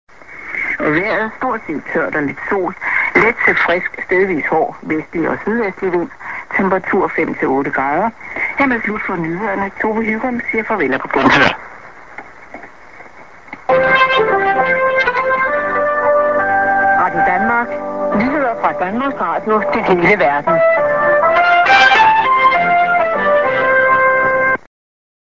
End prog->ST+ID(Women)